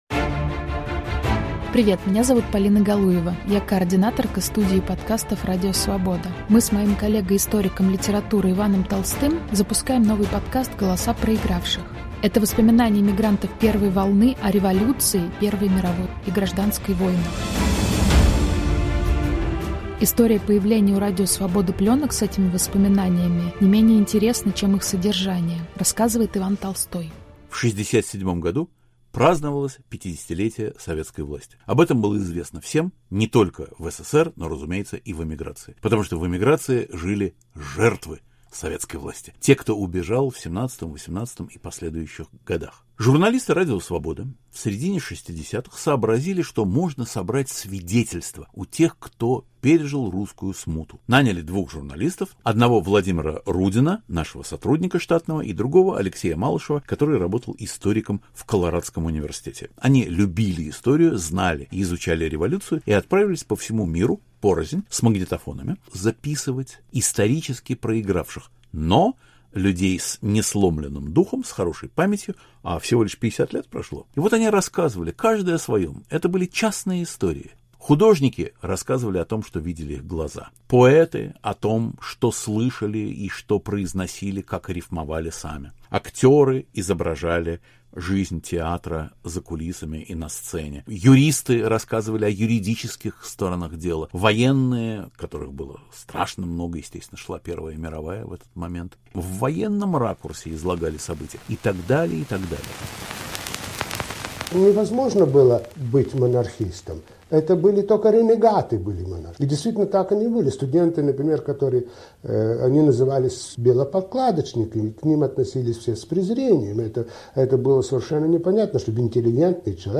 Воспоминания эмигрантов первой волны о революции 1917 года, Первой мировой и гражданской войнах. Голоса тех, кто видел гибель Российской Империи, из архива Радио Свобода